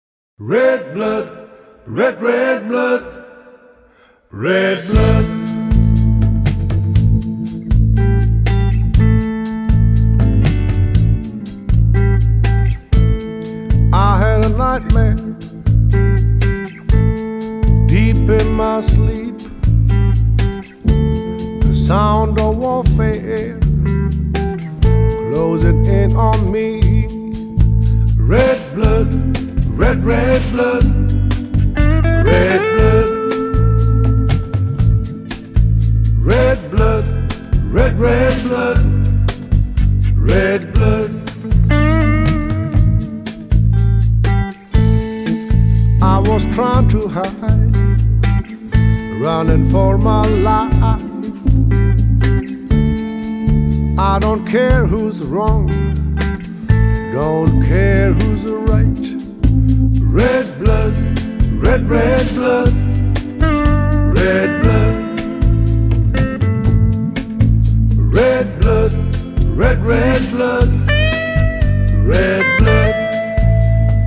發燒男聲、發燒天碟